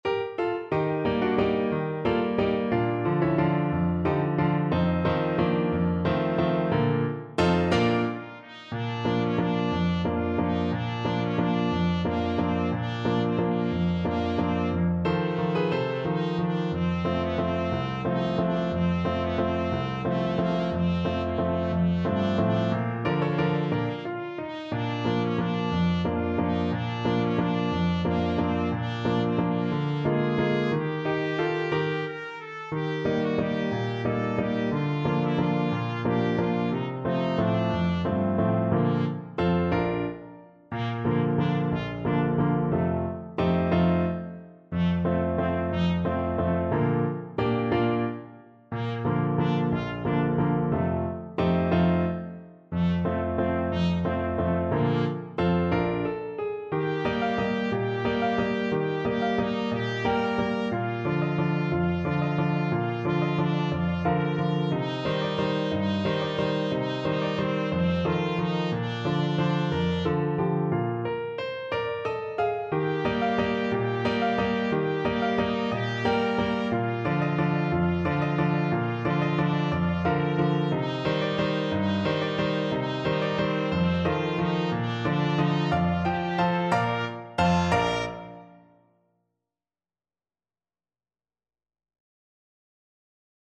3/4 (View more 3/4 Music)
A4-Bb5
One in a bar .=c.60
Trumpet  (View more Easy Trumpet Music)
Classical (View more Classical Trumpet Music)